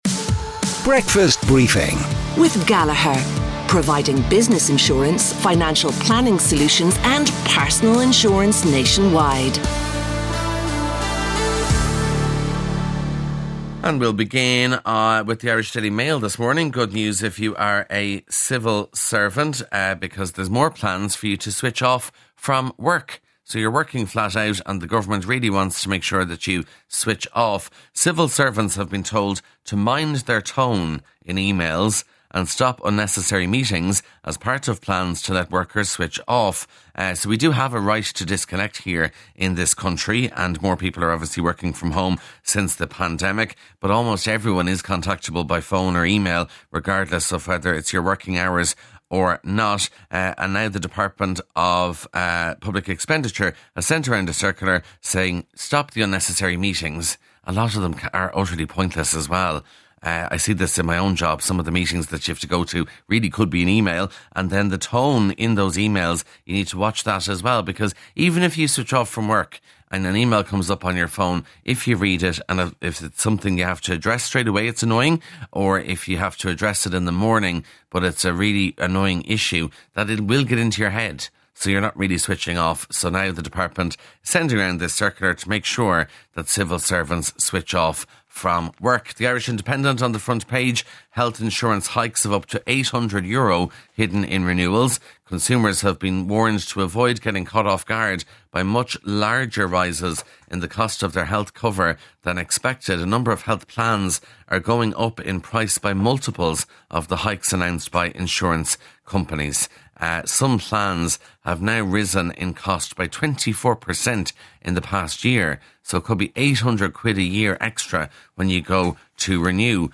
breakfast_briefing_newspaper_rev_40d05b4c_normal.mp3